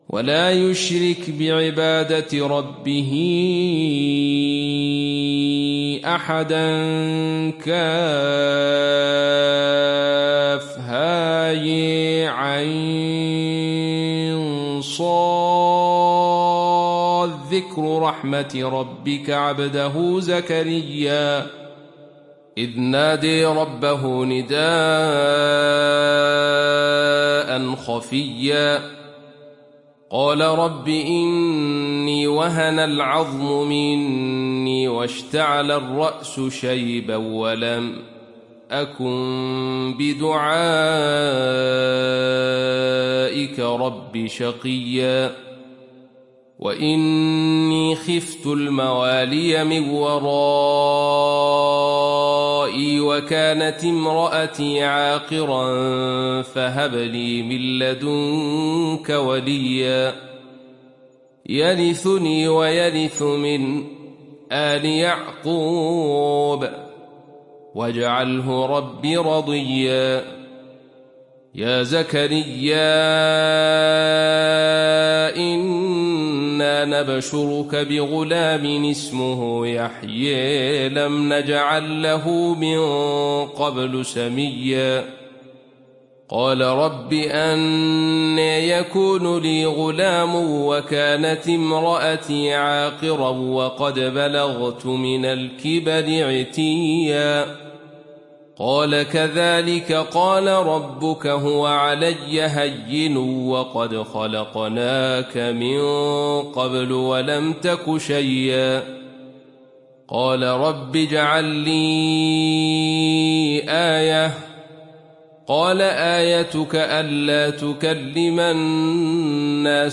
دانلود سوره مريم mp3 عبد الرشيد صوفي روایت خلف از حمزة, قرآن را دانلود کنید و گوش کن mp3 ، لینک مستقیم کامل